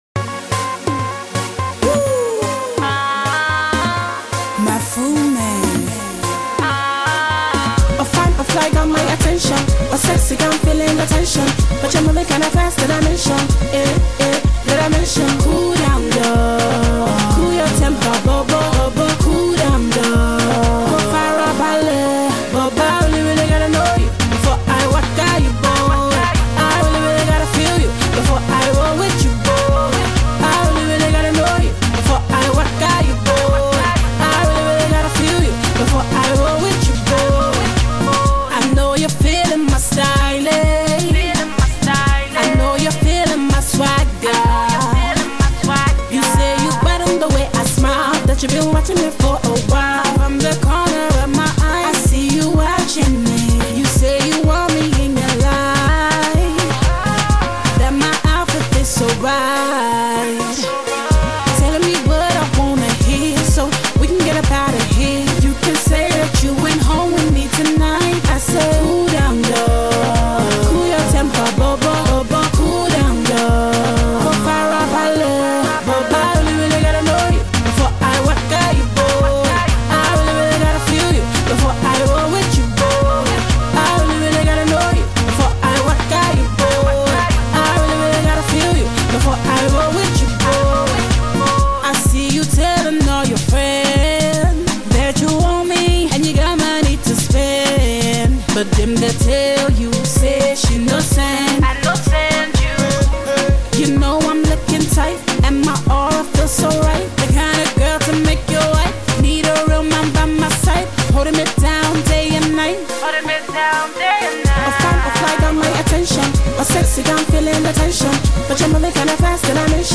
funky party tunes